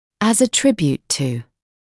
[æz ə ‘trɪbjuːt tuː][эз э ‘трибйуːт туː]как дань уважение по отношению к; как признание заслуг (кого-то)